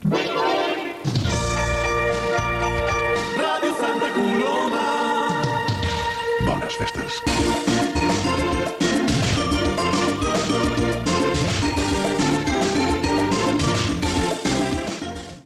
Indicatiu nadalenc de l'emissora
Banda FM